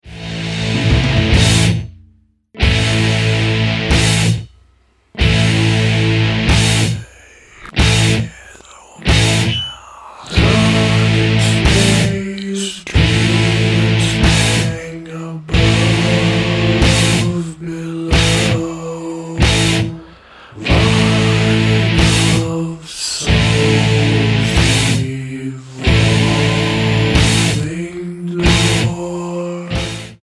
drums, guitars
bass guitar, voices